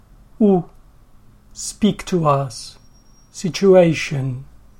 u (speak to us, situation)